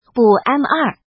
怎么读
ḿ